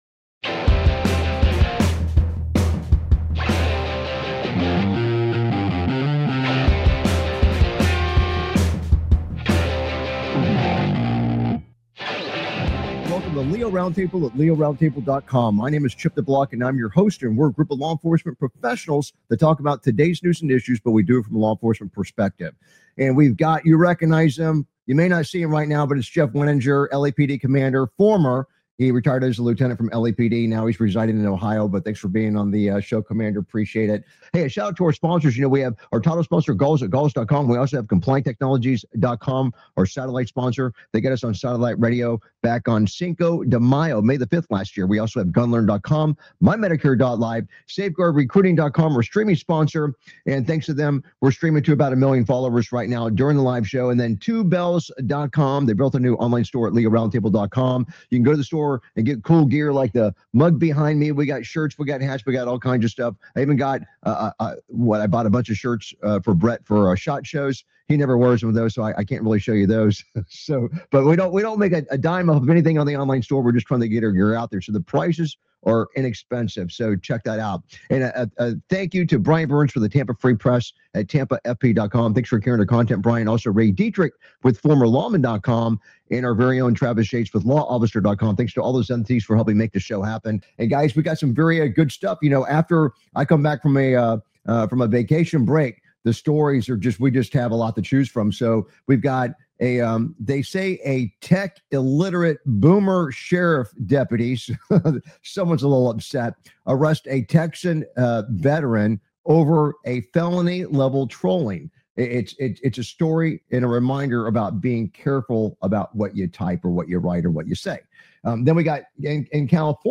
Talk Show Episode, Audio Podcast, LEO Round Table and S11E074, Gunman Tries A Standoff With Cops Before Being Shot On Video! on , show guests , about Veteran arrested over Felony-Level Trolling,California Supreme Court takes off warnings on citizen complaint forms,Multiple officers shot before gunman is taken out,Gunman tries a standoff with cops before being shot on video,Legal Rulings,Tactical Survival,LAPD Complaint Form Ruling,New Orleans Gas Station,Felony Online Impersonation,Texas Online Impersonation, categorized as Entertainment,Military,News,Politics & Government,National,World,Society and Culture,Technology,Theory & Conspiracy